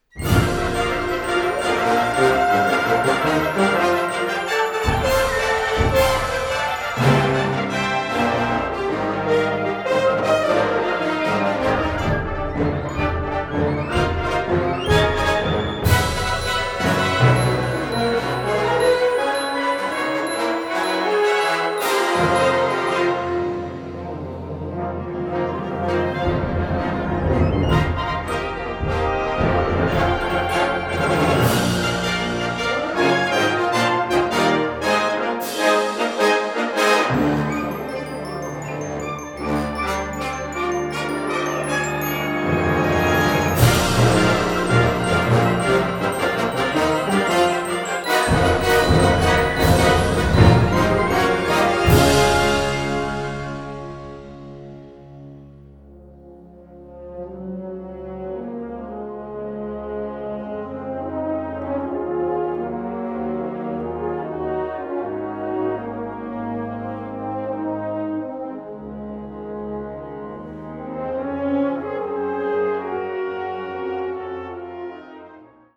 Catégorie Harmonie/Fanfare/Brass-band
Sous-catégorie Musique pour le début du concert
Instrumentation Ha (orchestre d'harmonie)
Une œuvre festive, joyeuse et spectaculaire !